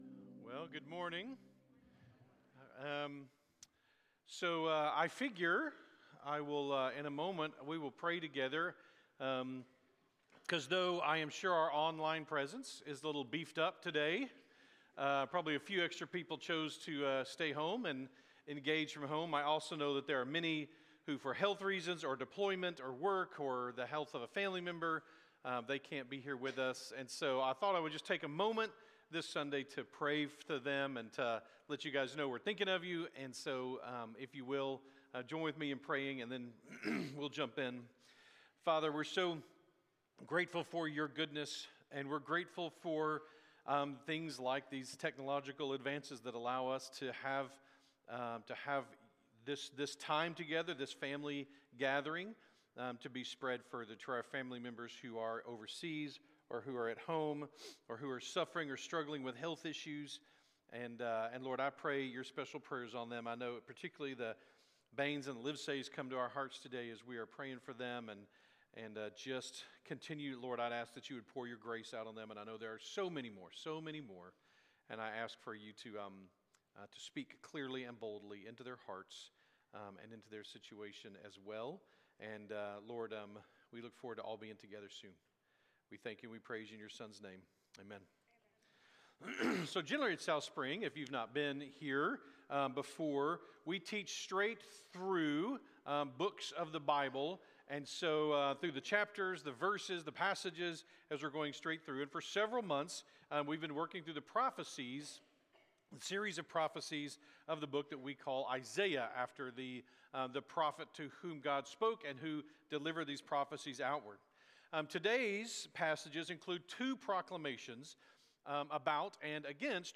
March-8-2026-Sunday-Morning.mp3